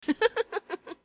Sound Effects
Soft Laughing